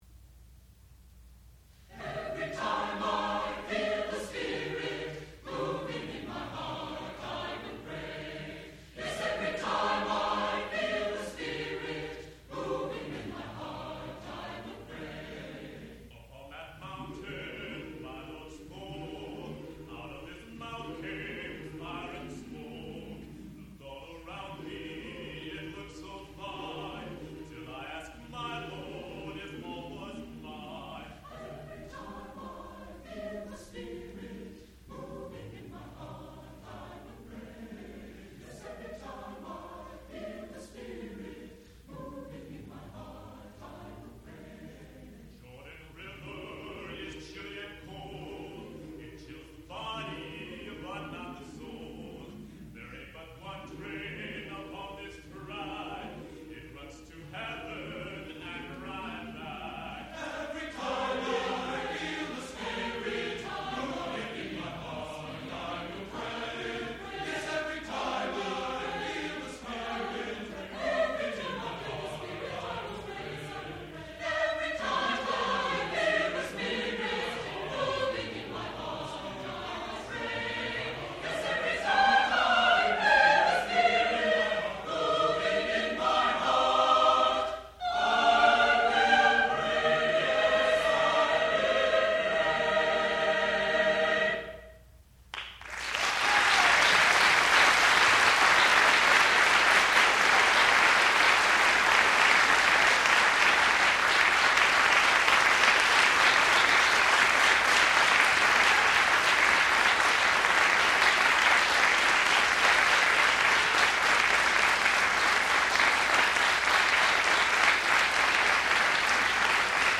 sound recording-musical
classical music
baritone